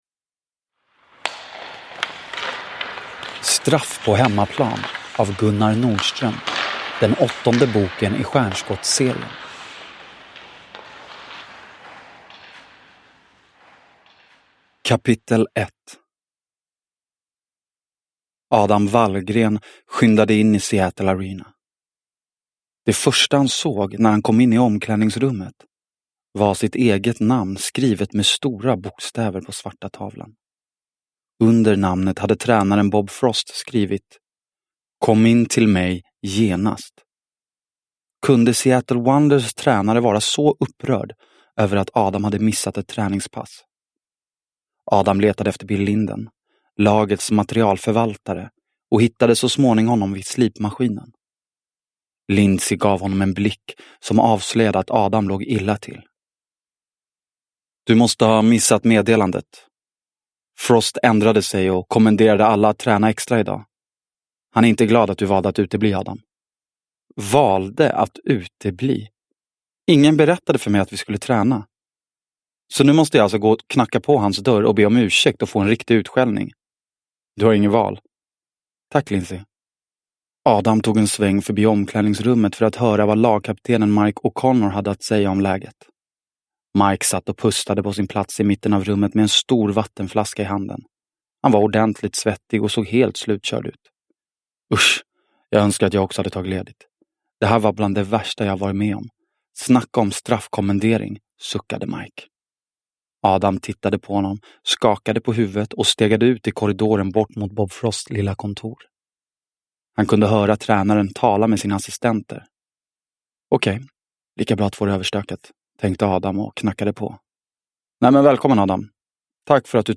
Straff på hemmaplan – Ljudbok – Laddas ner